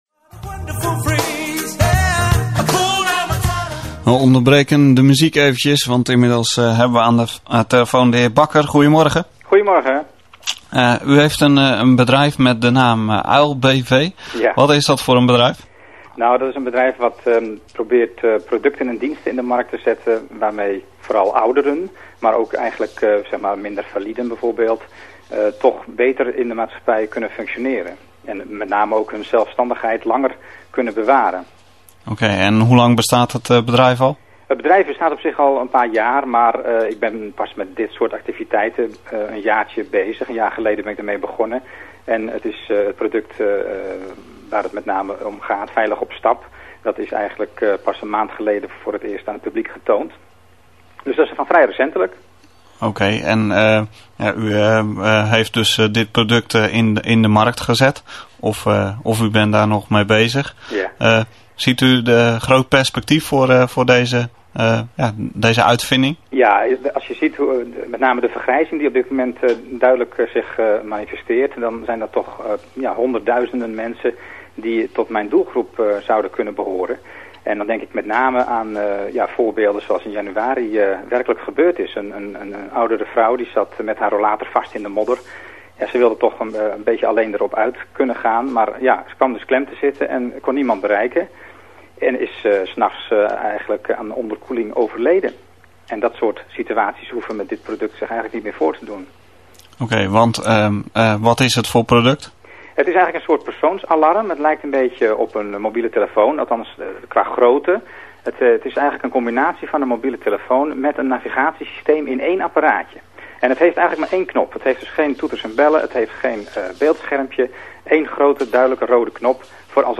Interview op radiozender Vlist AM